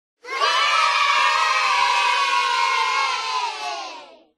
Play Kids Saying Yay - SoundBoardGuy
Play, download and share Kids saying yay original sound button!!!!
kids-saying-yay.mp3